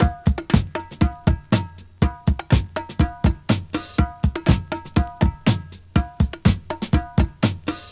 • 121 Bpm HQ Breakbeat Sample D# Key.wav
Free breakbeat sample - kick tuned to the D# note. Loudest frequency: 818Hz
121-bpm-hq-breakbeat-sample-d-sharp-key-Em5.wav